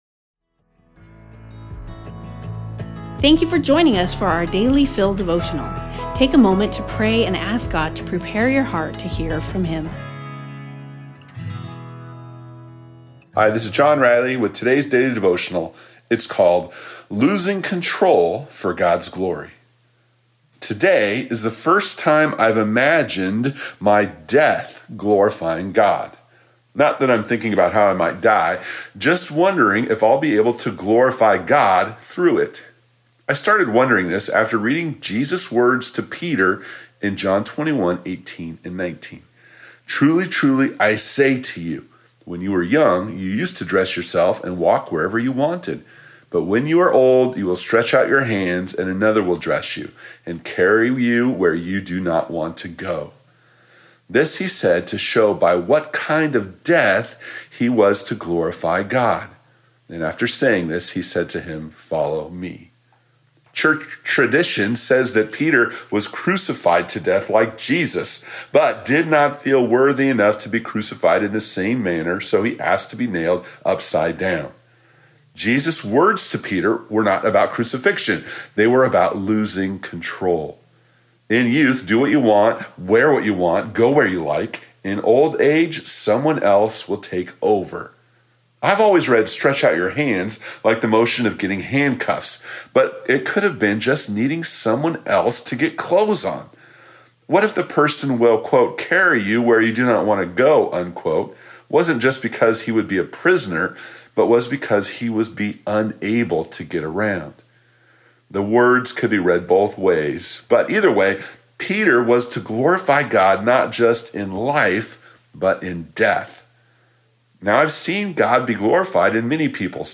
We hope you will enjoy these audio devotionals.